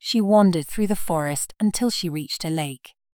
Kiejtés: /ˈwɒn.dər/